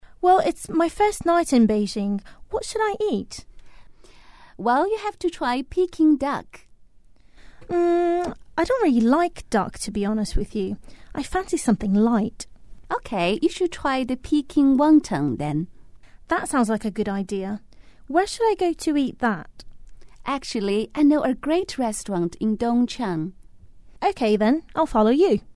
英语初学者口语对话第33集：第一次来北京该点吃什么？